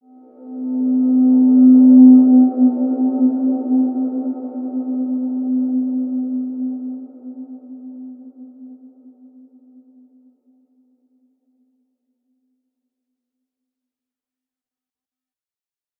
Large-Space-C4-p.wav